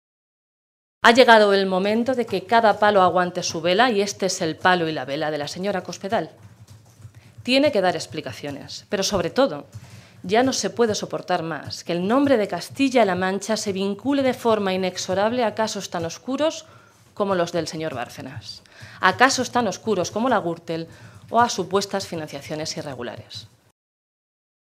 Blanca Fernández, diputada regional del PSOE de Castilla-La Mancha
Cortes de audio de la rueda de prensa